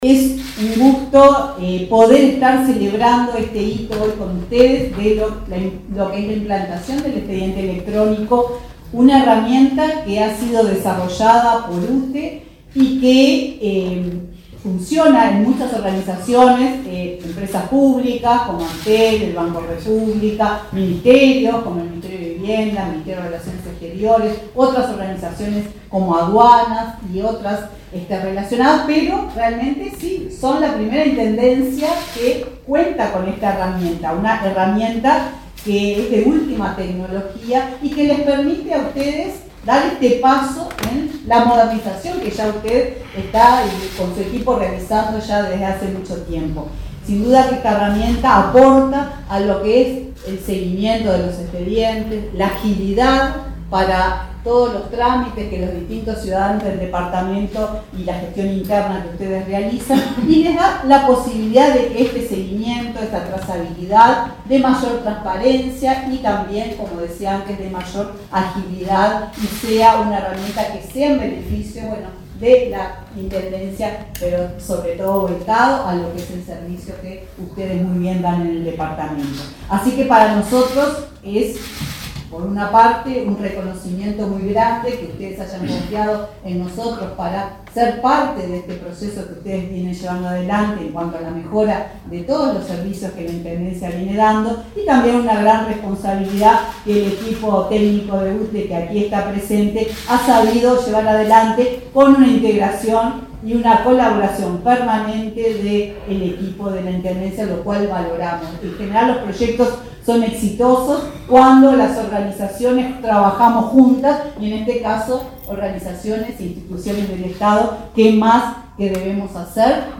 Palabras de la presidenta de UTE, Silvia Emaldi
Palabras de la presidenta de UTE, Silvia Emaldi 09/10/2023 Compartir Facebook X Copiar enlace WhatsApp LinkedIn La presidenta de la UTE, Silvia Emaldi, participó, en Colonia, en un acto, tras la implementación, en la intendencia, del expediente electrónico por la consultoría externa del ente.